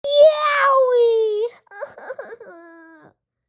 Yoweee Crying - Botão de Efeito Sonoro